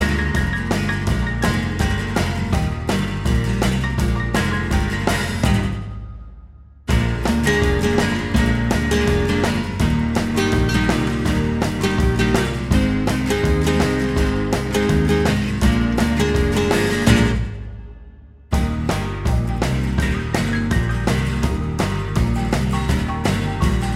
Minus Electric Guitar Rock 'n' Roll 2:35 Buy £1.50